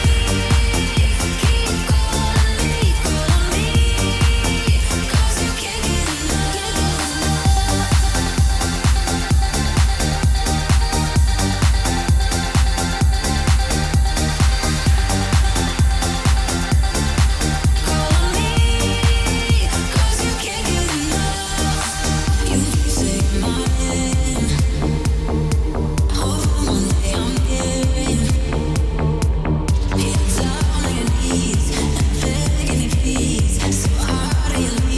Жанр: Танцевальные / Электроника